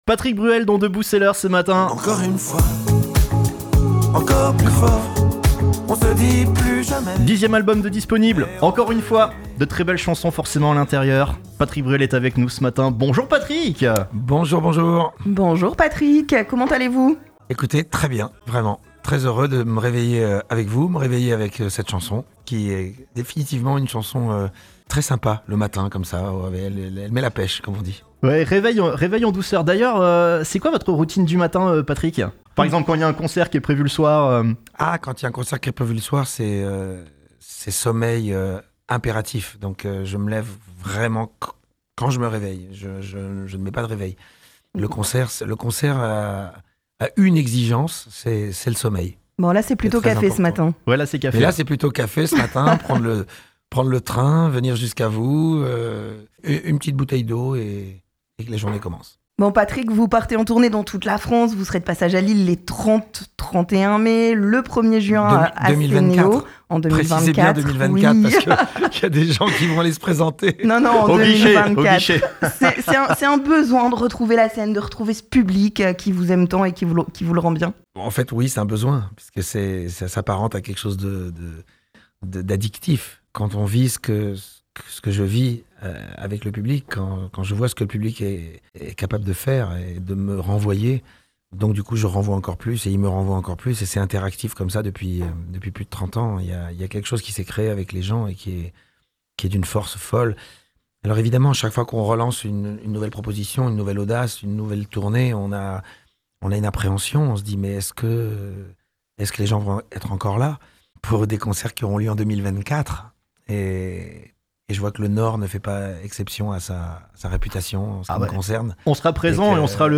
Patrick Bruel invité de Debout C'est l'Heure sur RDL !
interview